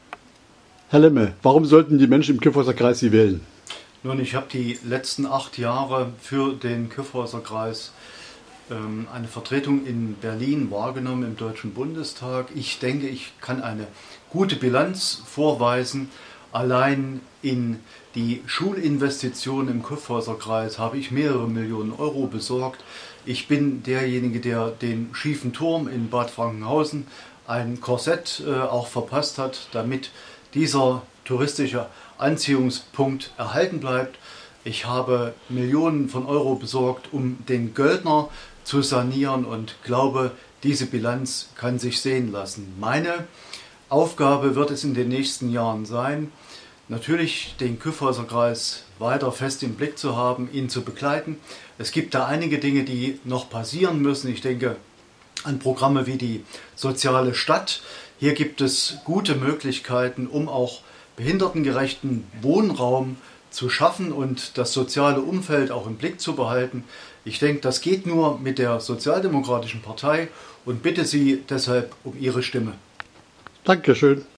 Am Vormittag gab es einen Gesprächsstand in der Hauptstraße von Sondershausen vor der Geschäftsstelle der SPD
Wie will Lemme die Wähler gewinnen? Für Sie hat kn nachgefragt: